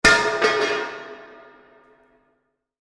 metal_bang3.mp3